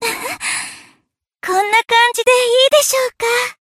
贡献 ） 分类:蔚蓝档案语音 协议:Copyright 您不可以覆盖此文件。
BA_V_Hanako_Tactic_Victory_1.ogg